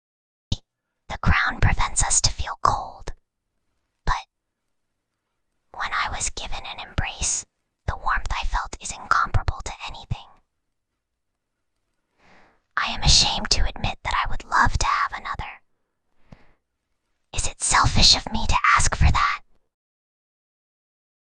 Whispering_Girl_27.mp3